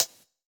UHH_ElectroHatD_Hit-07.wav